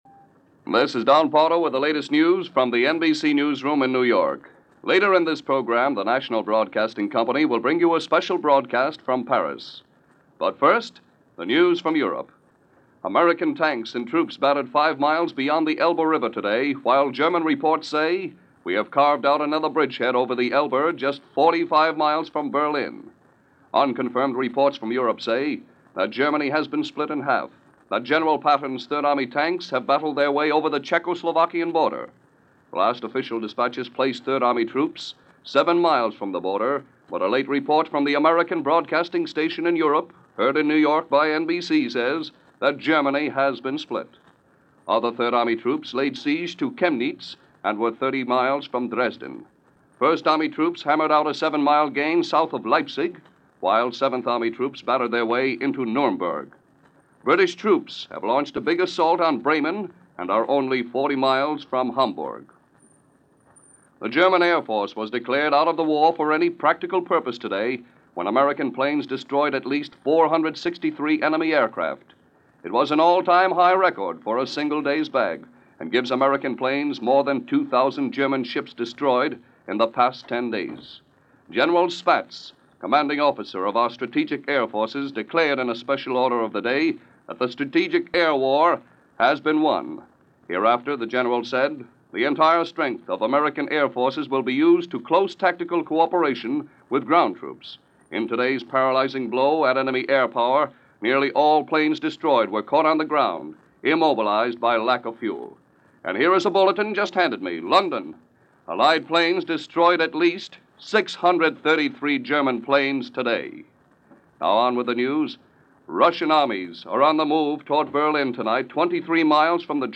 April 16, 1945 - 45 Miles From Berlin - Crossing The Elbe - End Of The Air War In Europe - news for this day in 1945 - NBC Radio.